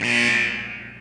Add ambiance and packing buzzers
buzzer_wrong.wav